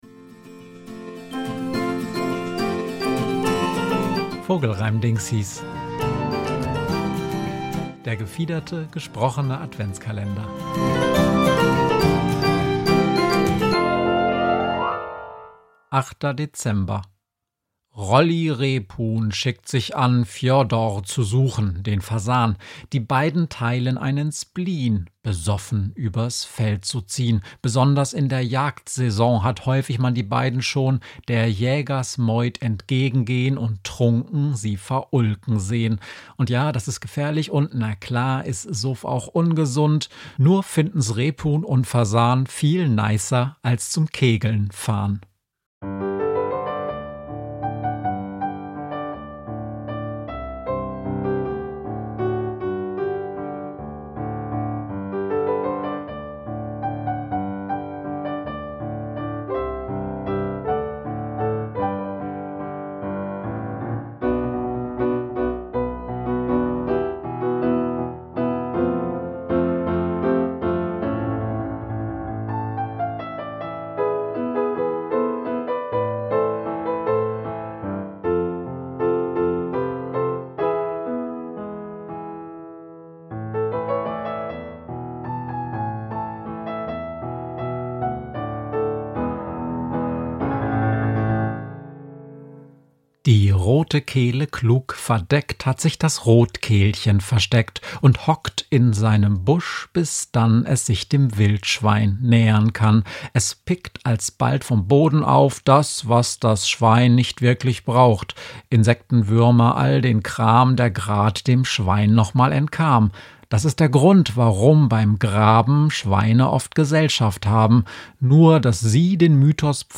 gesprochene Adventskalender